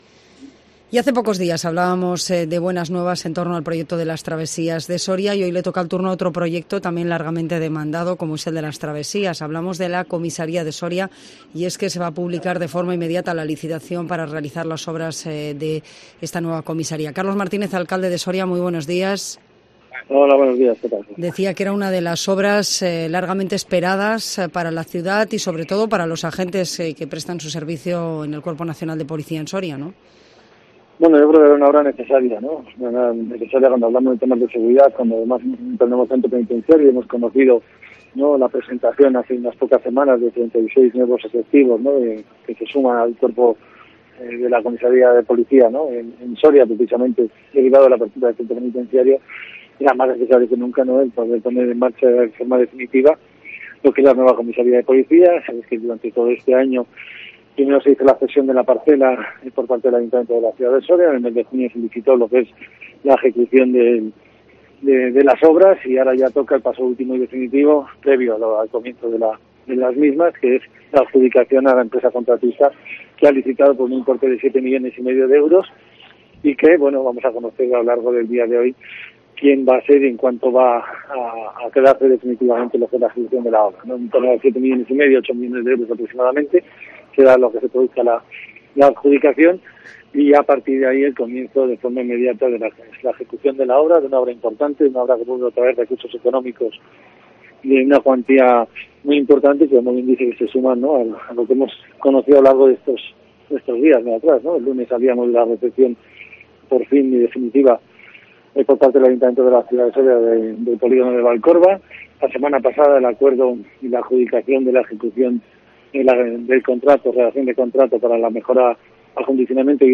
Carlos Martínez habla en Cope Soria de la adjudicación de las obras de la nueva comisaría del CNP en Soria